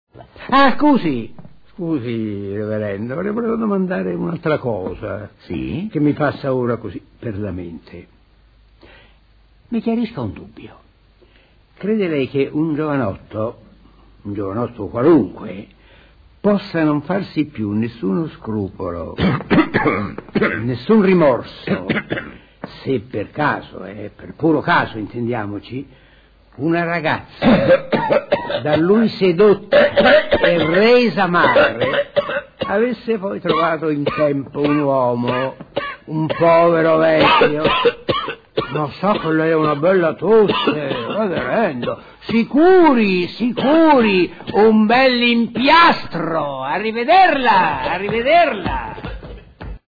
IL PROFESSOR TOTI: Sergio Tofano